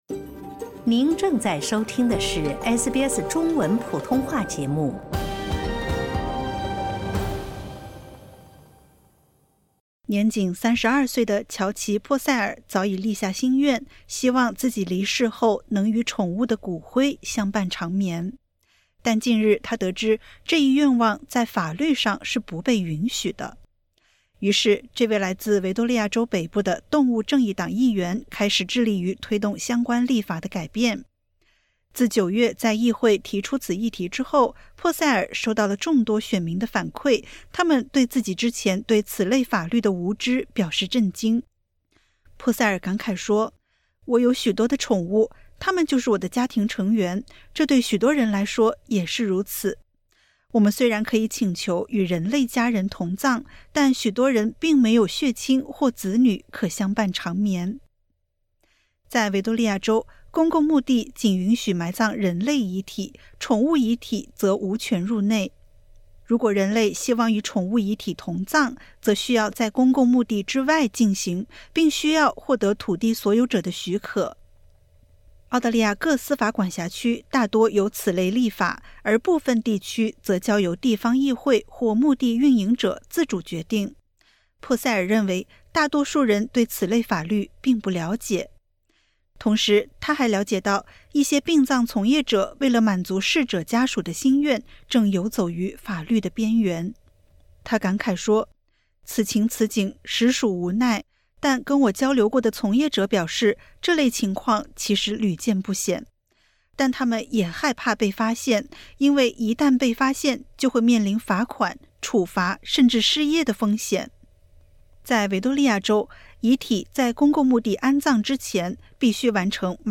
在众多家庭中，宠物占据着举足轻重的地位。然而，在许多公共墓地，法律却禁止宠物与人类同葬。点击 ▶ 收听完整报道。